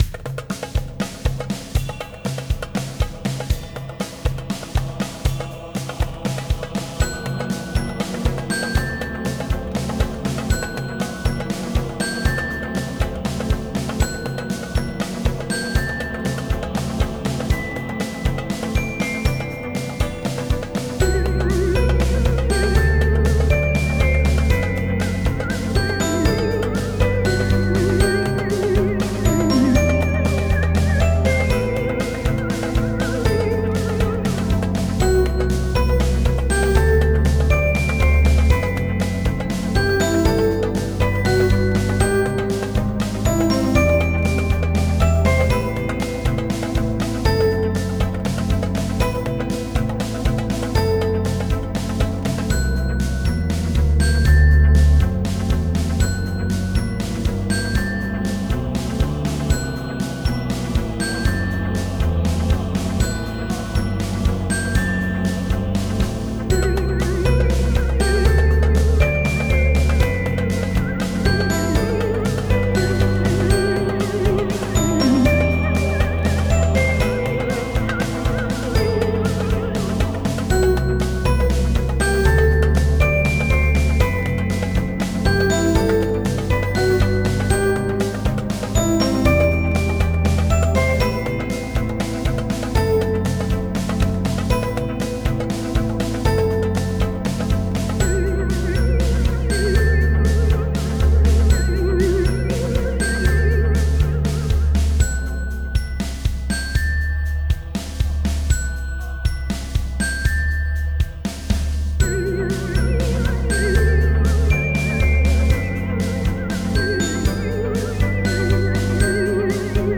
• リズム構成：軽快なパーカッションが曲全体を引っ張ります。やや跳ねるようなノリ。
• コード進行：長調ベースですが、部分的に短調や不協和音を挟むことで“不穏さ”を表現。
• メロディ：オルゴール風のシンセ音、マリンバ、アコーディオン系サウンドなどを使用。
• ループ対応：1分52秒で自然にループできる構成。ゲームBGM向けに最適です。